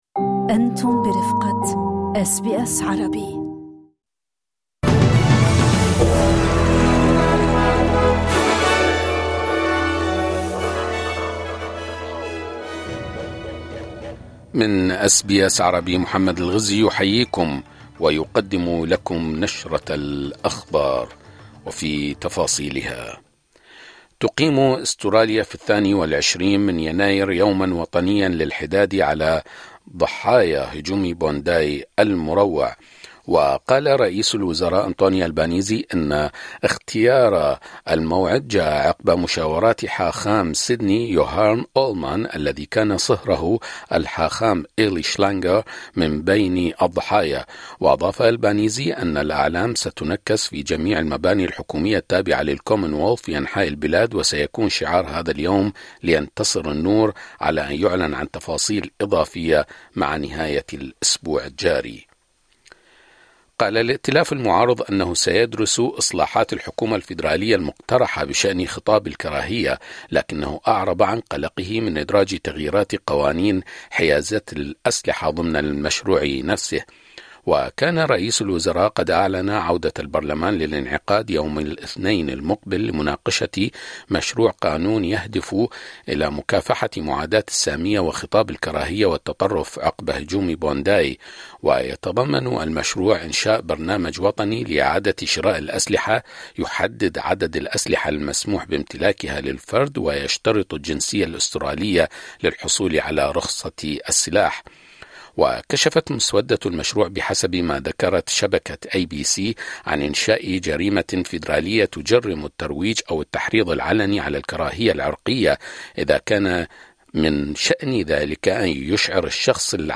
نشرة أخبار الظهيرة 13/01/2025